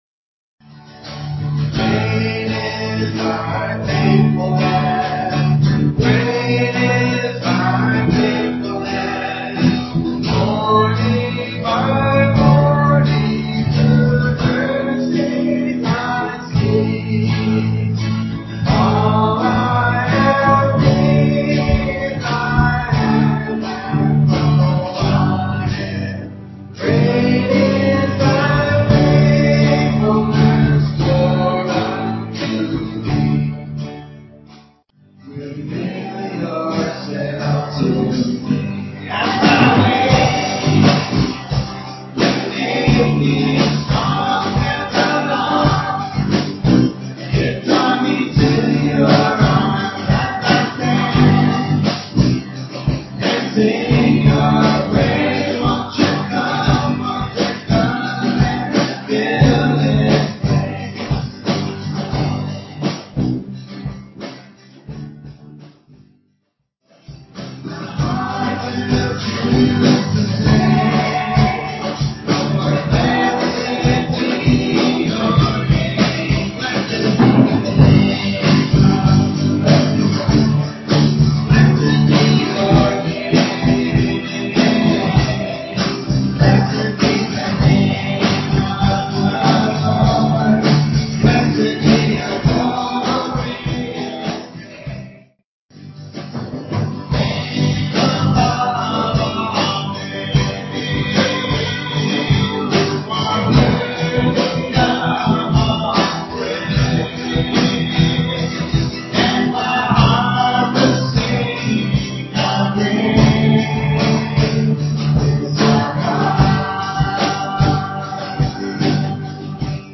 guitar and vocals
keyboard and vocals
bass guitar
drums.